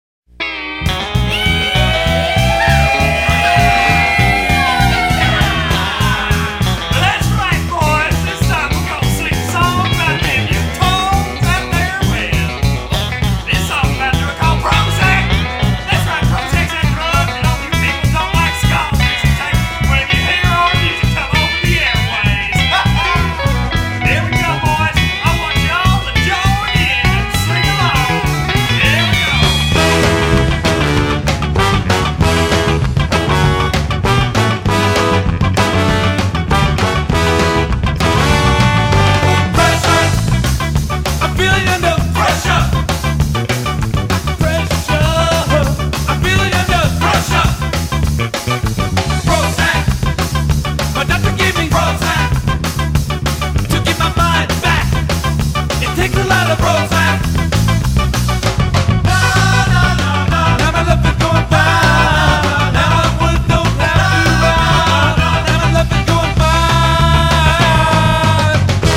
six-piece, energetic hyper-ska for boozin' skankers.